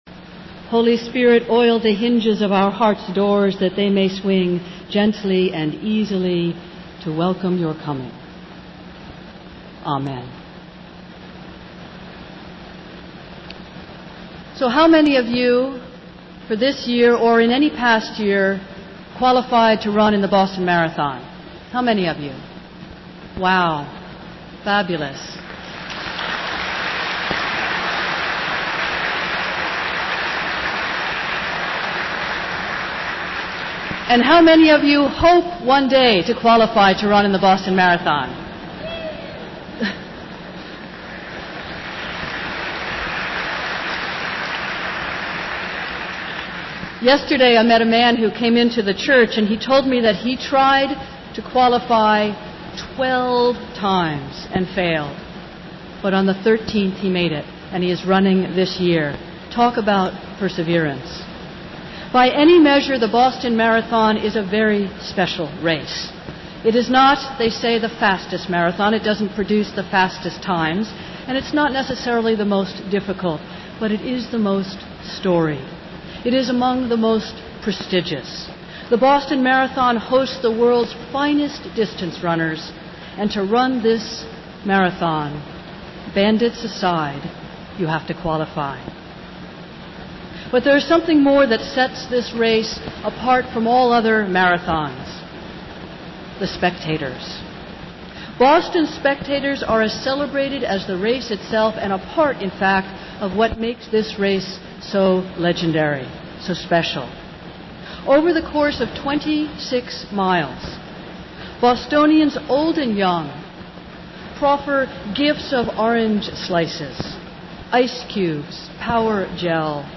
Festival Worship - Marathon Sunday and Blessing of the Athletes